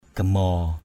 /ɡ͡ɣa-mɔ:/ (t.) rám = sombre. sunburned, tanned. mbaok gamaow _O<K g_m<| mặt rám = visage sombre. tanned face.